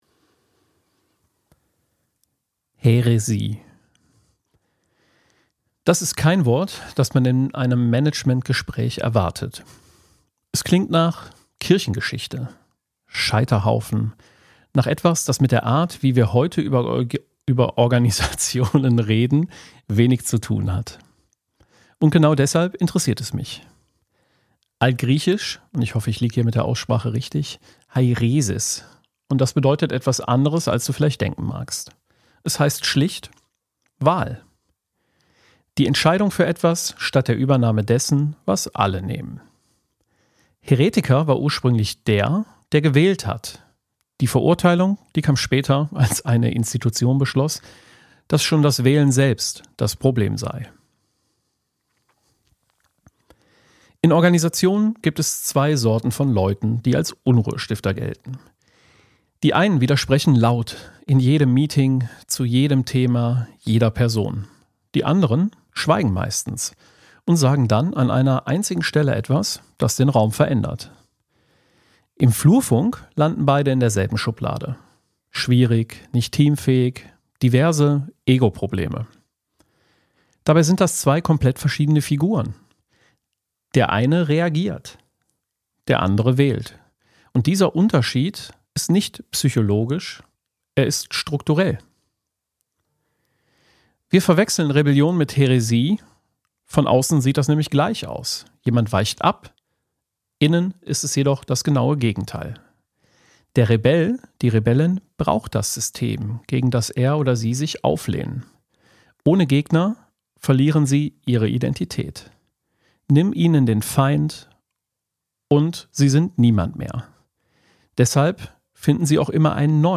Kein Jingle, kein Small Talk.